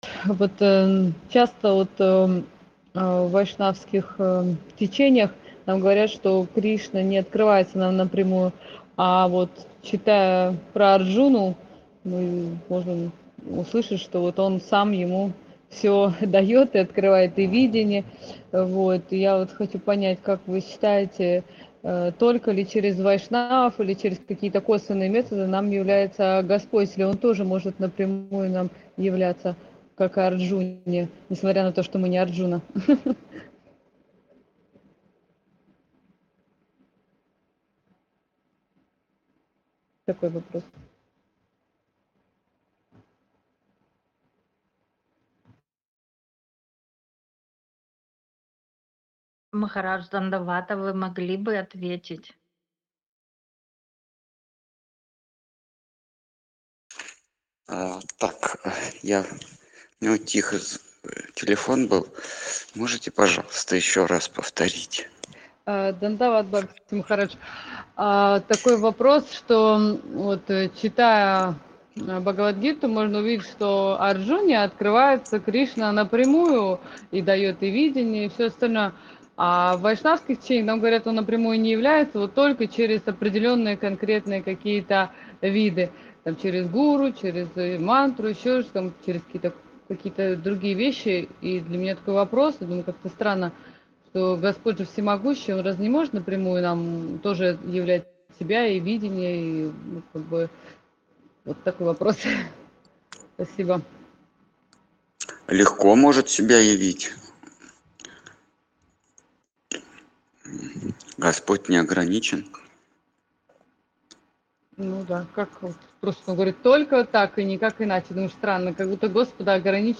Ответы на вопросы из трансляции в телеграм канале «Колесница Джаганнатха». Тема трансляции: Бхагавад Гита.